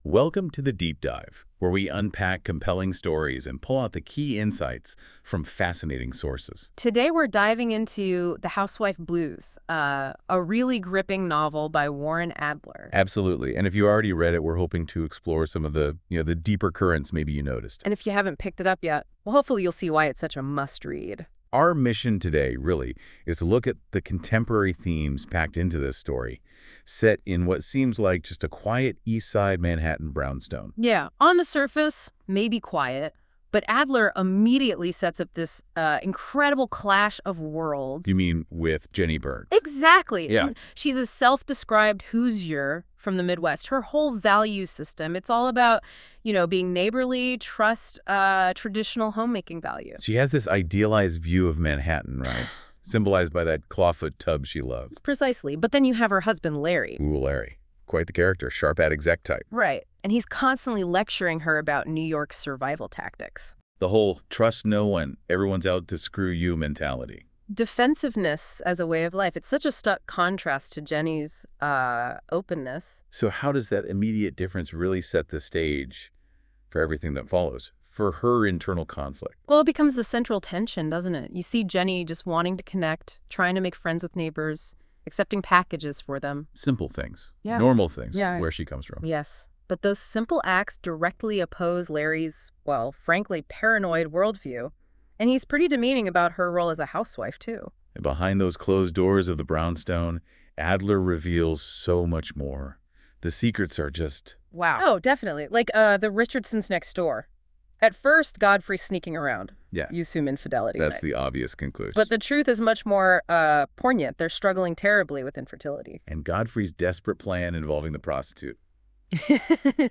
This episode: A lively and insightful conversation exploring the themes, characters, and deeper meanings within Warren Adler’s The Housewife Blues